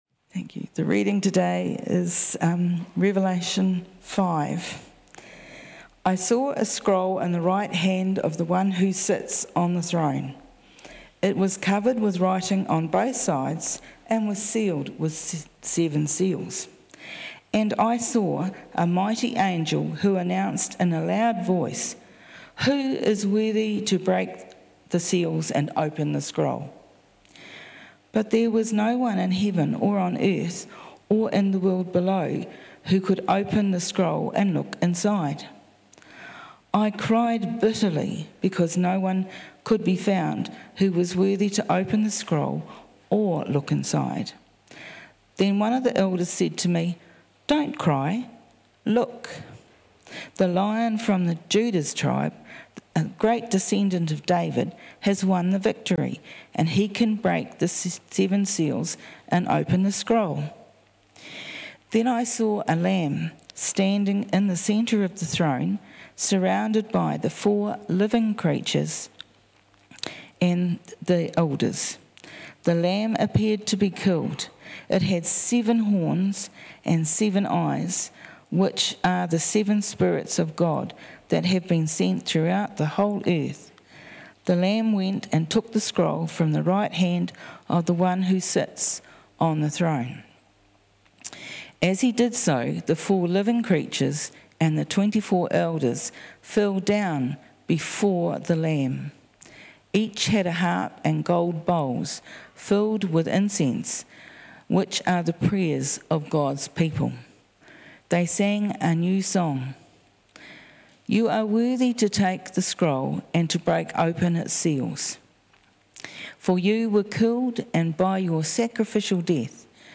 Sermons | Whanganui Anglicans